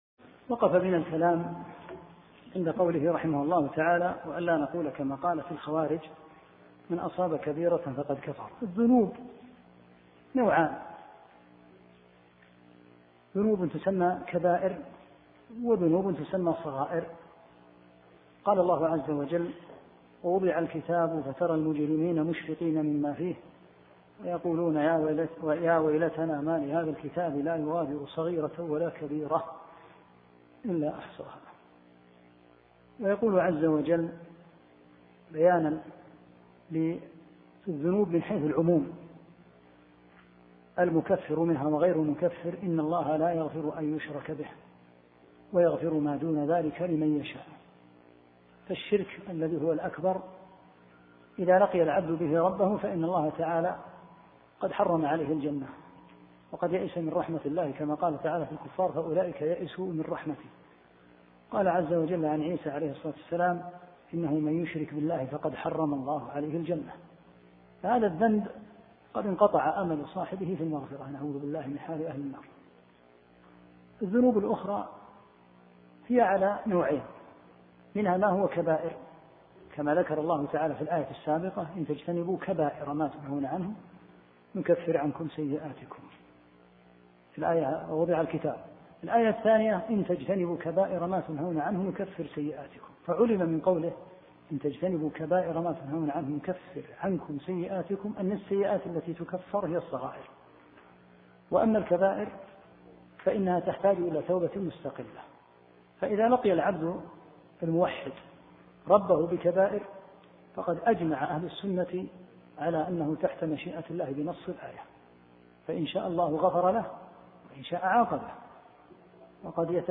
2- الدرس الثاني شرح مختصر اصول السنة للحميدي